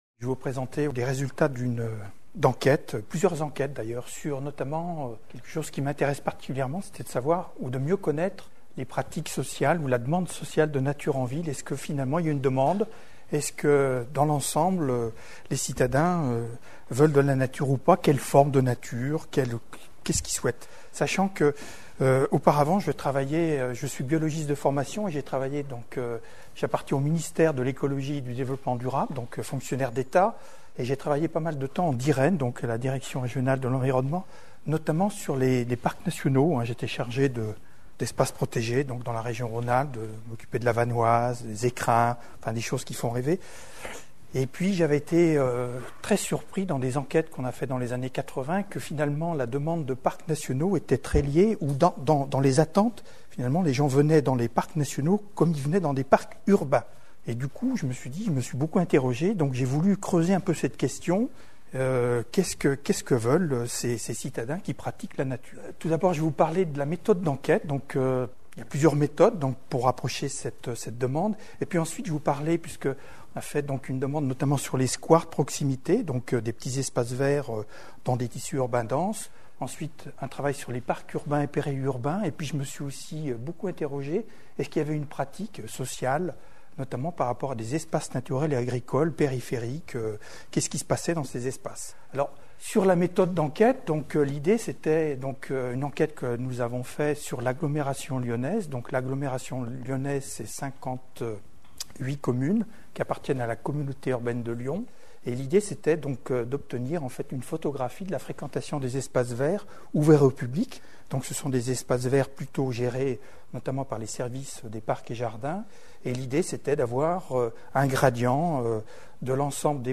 L'exposé